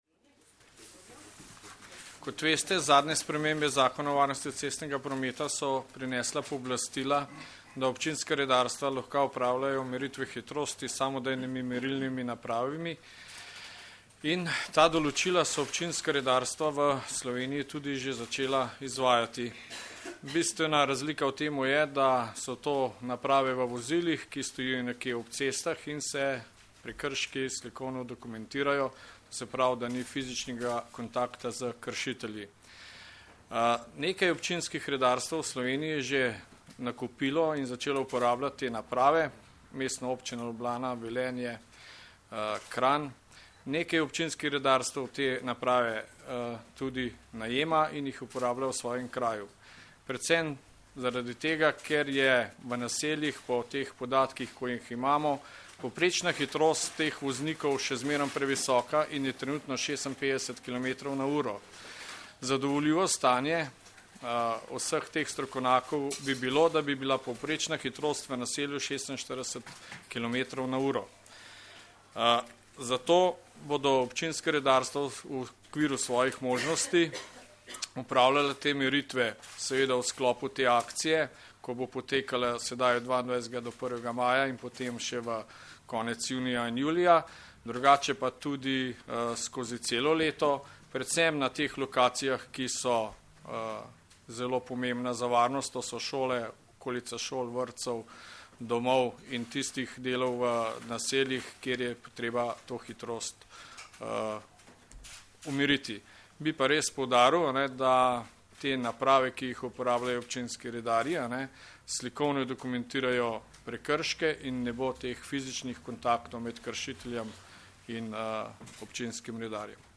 Policija - Policisti začeli s poostrenim nadzorom hitrosti - preventivna kampanja Hvala, ker voziš zmerno - informacija z novinarske konference
Zvočni posnetek izjave